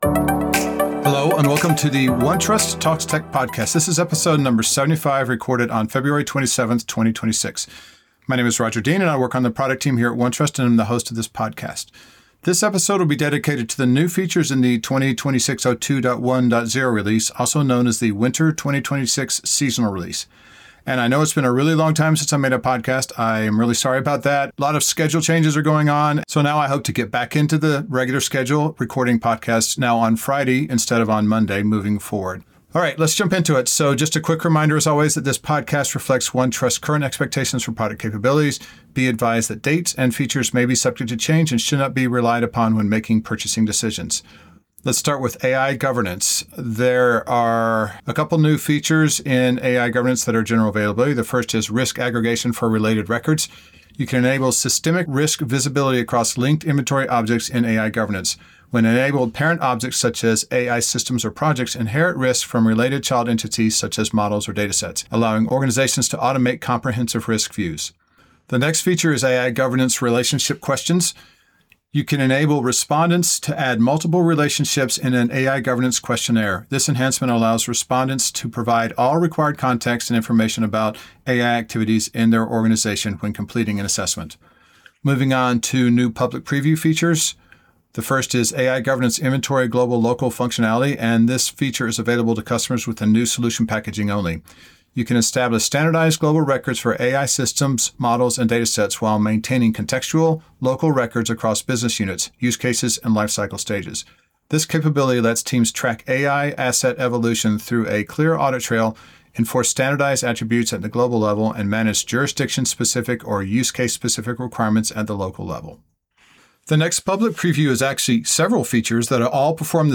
The format of the podcast is in two parts, part 1 is an interview with an industry or product expert and part 2 is a summary of the latest product release.